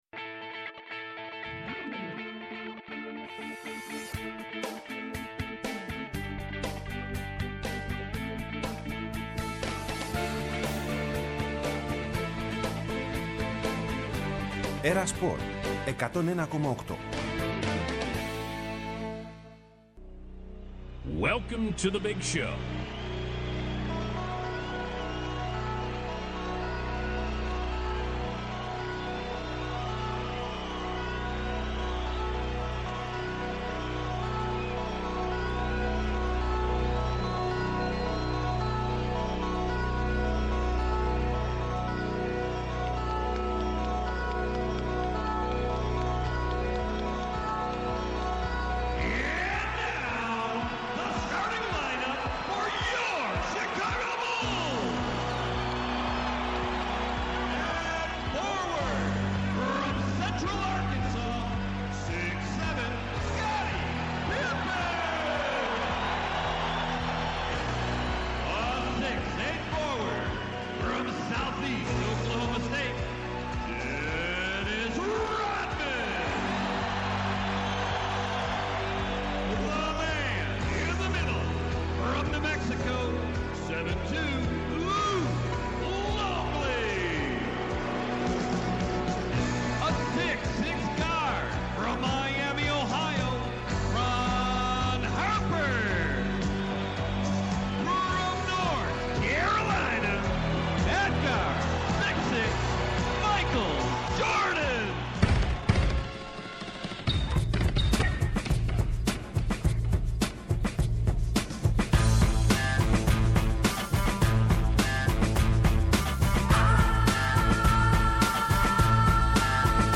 24 δευτερόλεπτα… Η μοναδική, καθημερινή, εκπομπή μπάσκετ στα ερτζιανά.